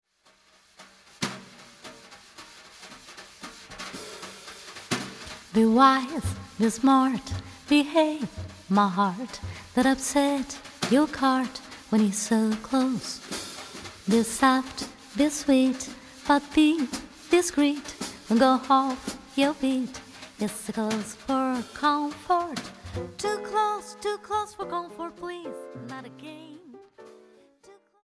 voice
piano
bass
drums